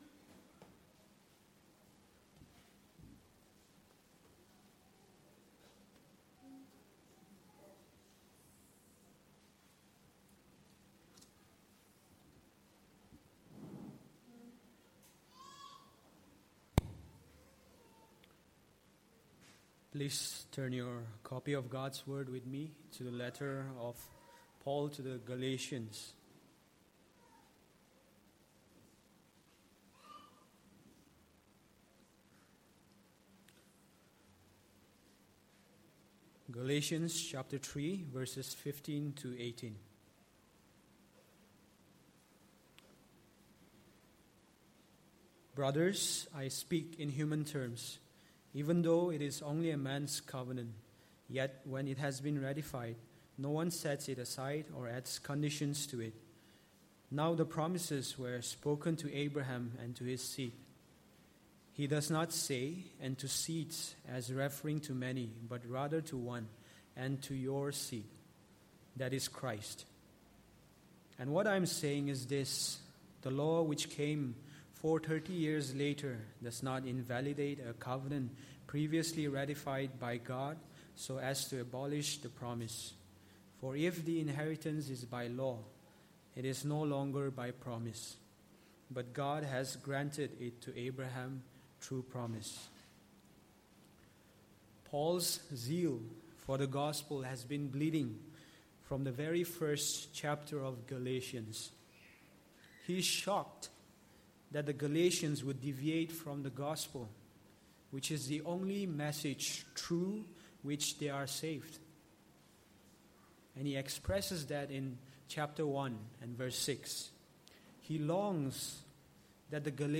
Passage: Galatians 3:15-18 Service Type: Sunday Morning